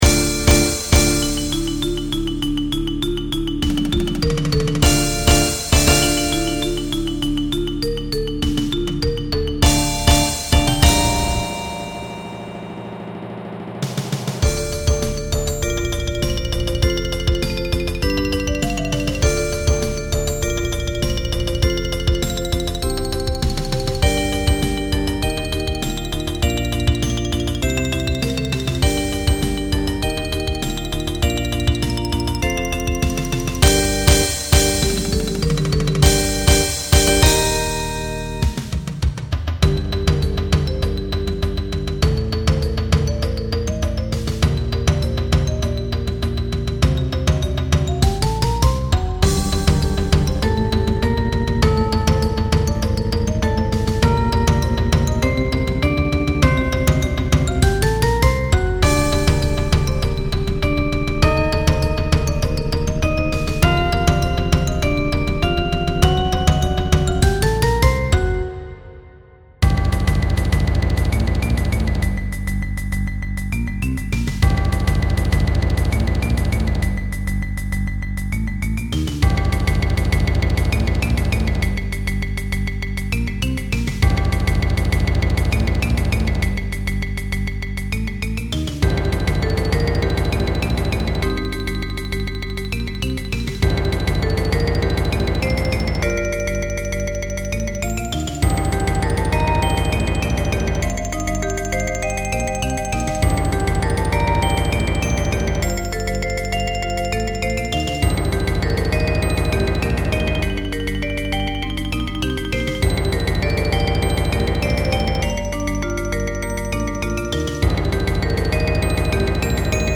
Rubriek: Mallet-Steelband Muziek